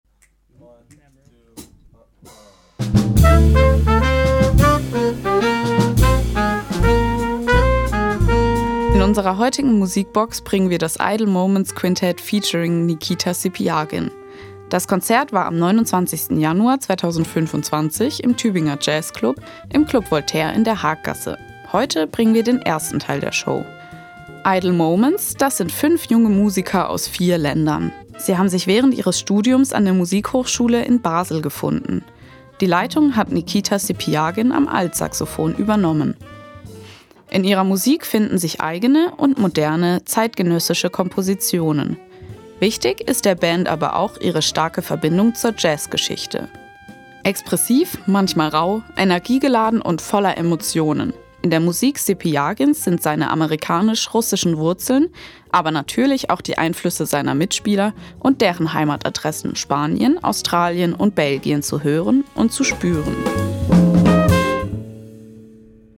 Altsaxofon
Trompete
Nord-Piano
Bass
Schlagzeug